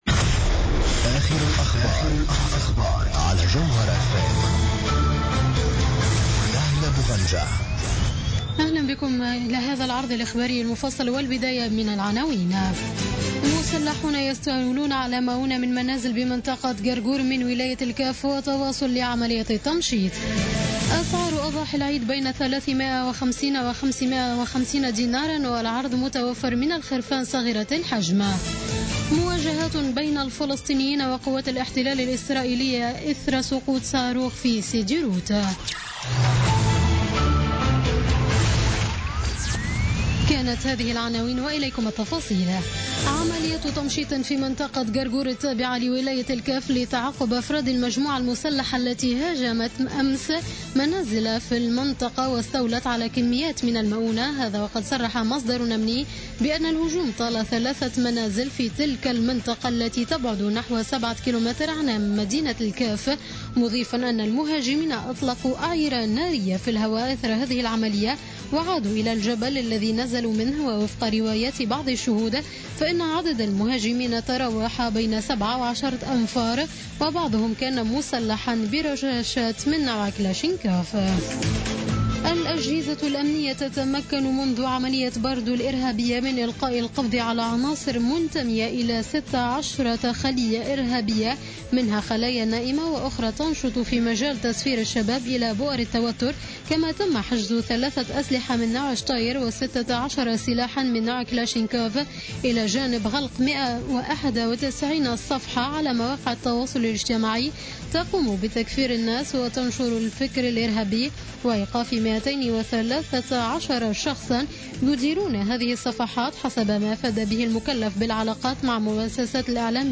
نشرة أخبار منتصف الليل ليوم السبت 19 سبتمبر 2015